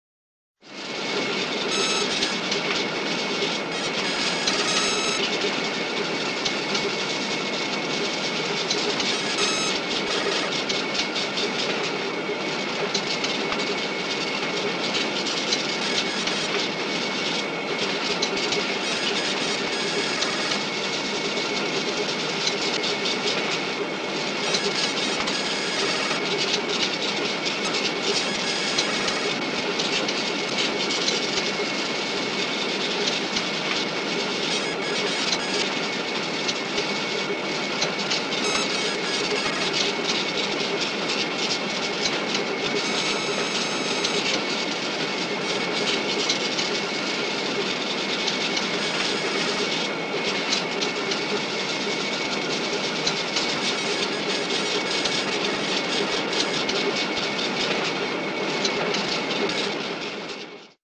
BSG FX - Ambience of control room and computers
BSG_FX_-_Ambience_of_Control_Room_and_Computers.wav